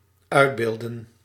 Ääntäminen
Synonyymit voorstellen afschilderen Ääntäminen NL: IPA: /ˈœy̯dˌbeːldə(n)/ IPA: /ˈʌy̯dˌbeːldə(n)/ BE: IPA: /ˈœy̯dˌbeːldə(n)/ IPA: /ˈœːdˌbeːldə(n)/ Haettu sana löytyi näillä lähdekielillä: hollanti Käännöksiä ei löytynyt valitulle kohdekielelle.